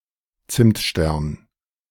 A Zimtstern (German pronunciation: [ˈt͜sɪmtˌʃtɛʁn]
De-Zimtstern.ogg.mp3